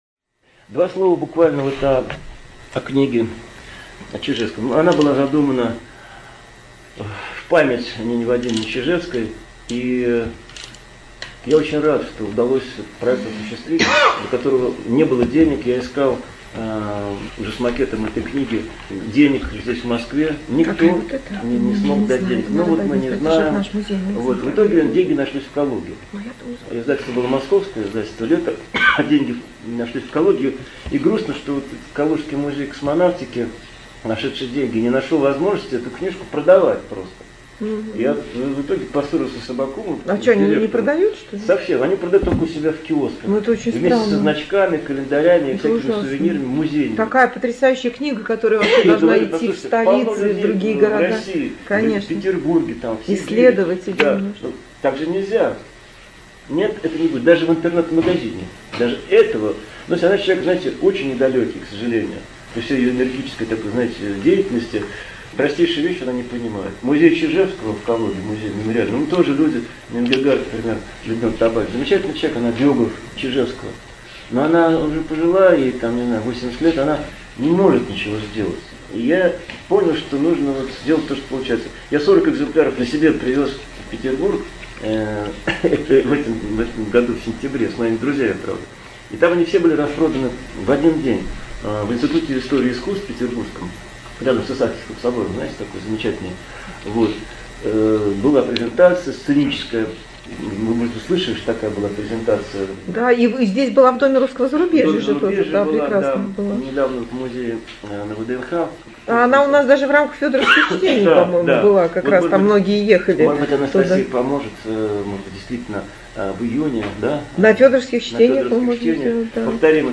Презентация книги «Под бесконечным небом»на книжной ярмарке non/fictionЦентральный Дом художника, 28 ноября 2018 г.